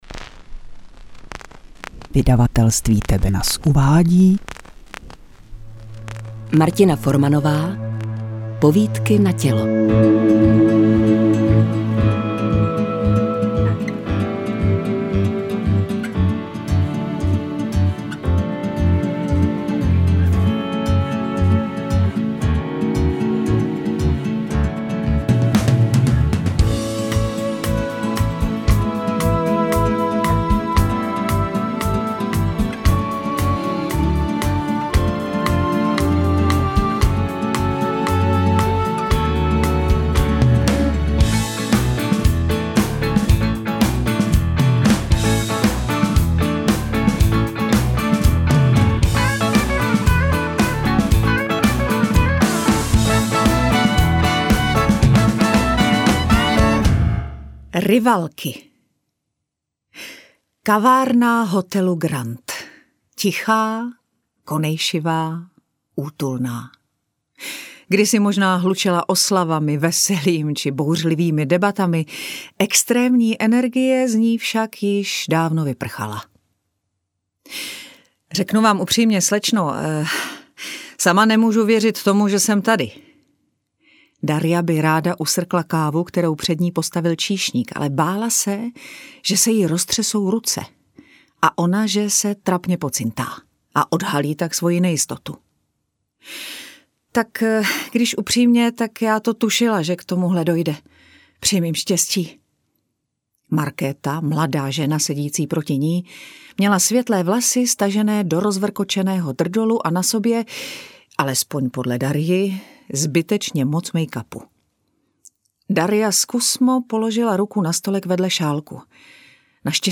Interpreti:  Lucie Juřičková, Simona Postlerová, Martin Zahálka
AudioKniha ke stažení, 17 x mp3, délka 4 hod. 8 min., velikost 351,1 MB, česky